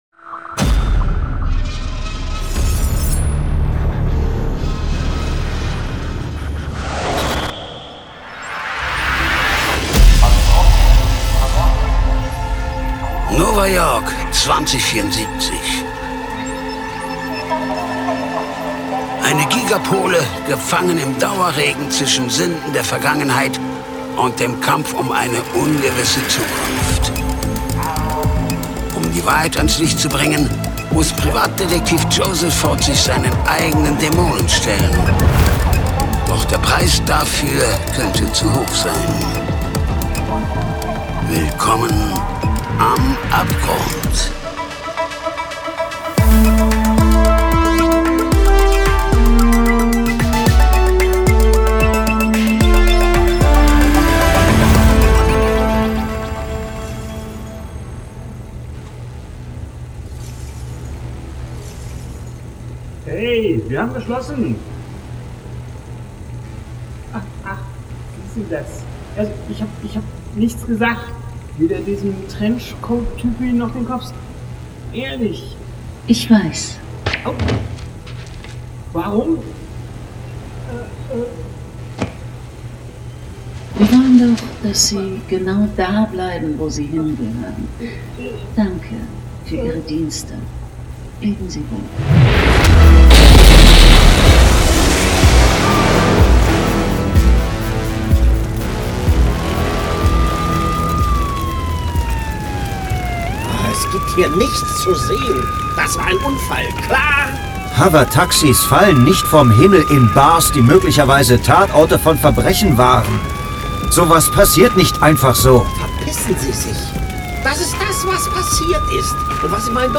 Die SciFi-Noir-Krimi-Hörspielserie Podcast